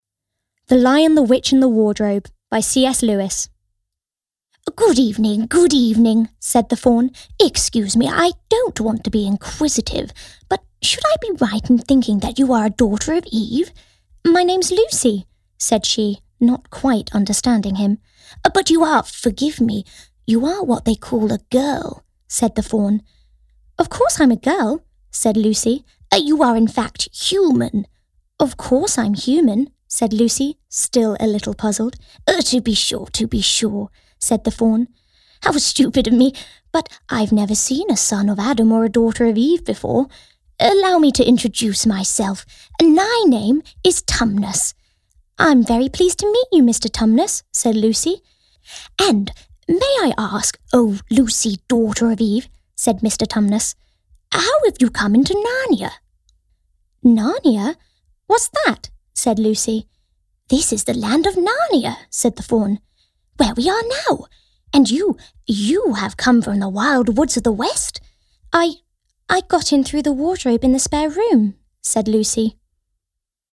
Audio Book/Audio Drama
Standard English/RP, London/Cockney, American, Yorkshire, Irish
Actors/Actresses, Corporate/Informative, Modern/Youthful/Contemporary, Natural/Fresh, Smooth/Soft-Sell, Quirky/Interesting/Unique, Character/Animation, Upbeat/Energy